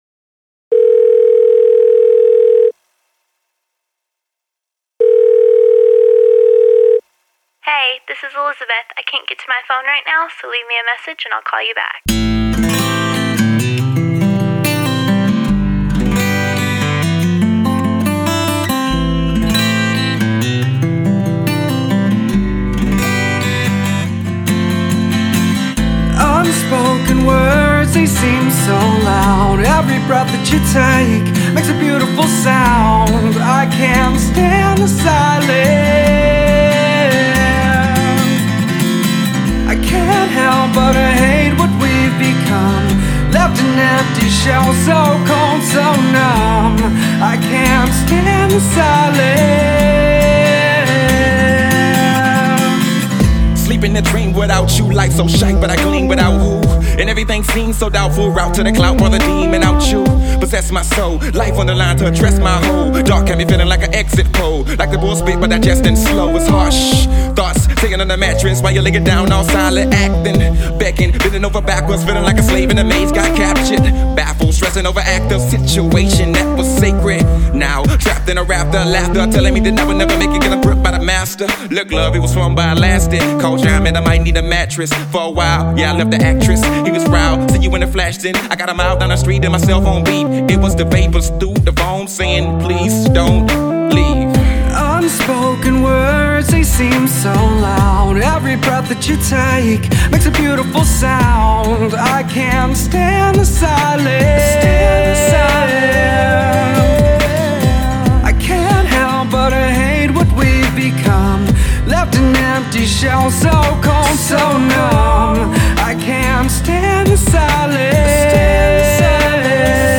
dance/electronic
Hip-hop
Industrial
Experimental